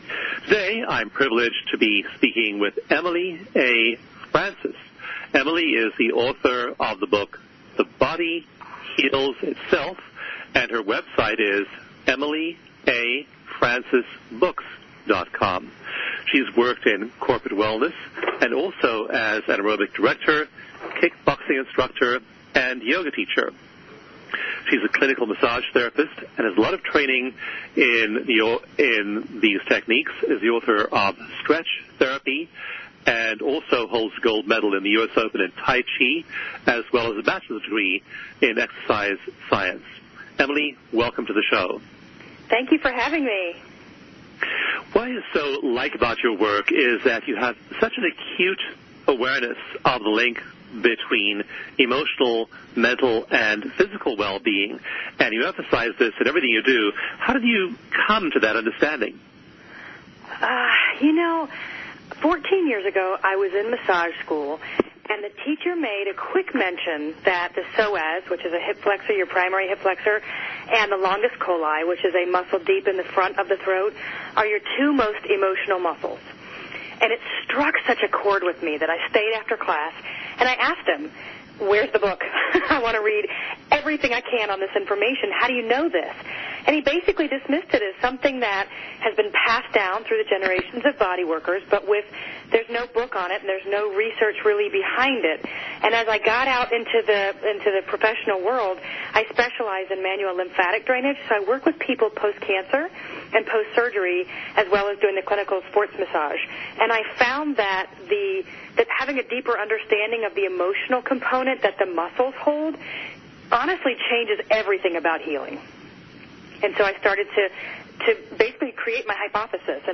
Interview on High Energy Health